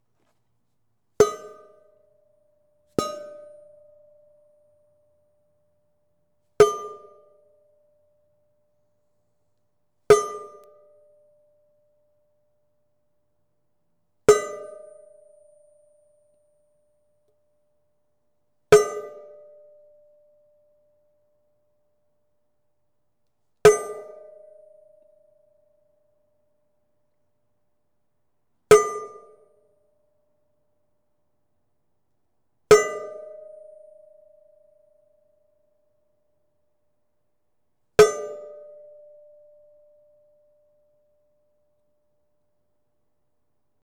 Aluminum_Plate_Suspended_1
aluminum clang clank ding hit impact industrial metal sound effect free sound royalty free Sound Effects